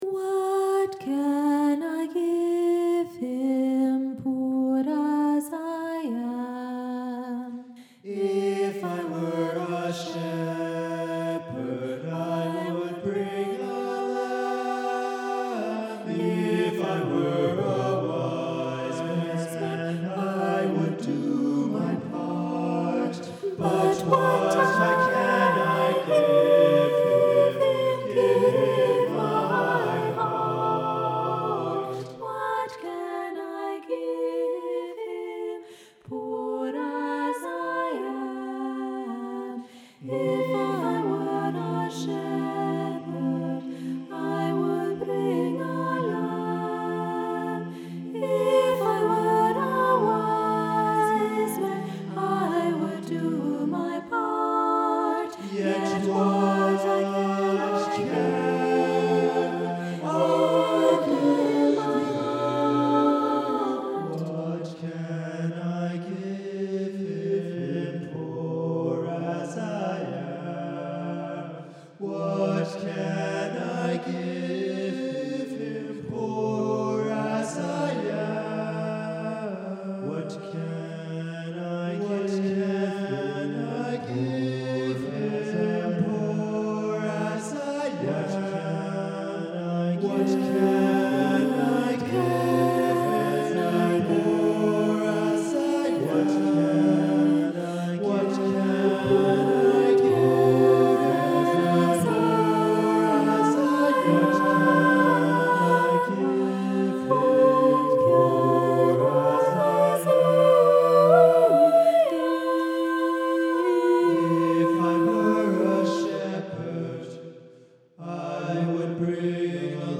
Give Him My Heart, for SATB, words by Christina Rossetti
I wrote my first piece for all voices. I took some of my favourite Christmas words from the famous poem “In the Bleak Midwinter”, by Christina Rossetti.